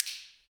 Index of /90_sSampleCDs/Roland L-CDX-01/PRC_Clap & Snap/PRC_Snaps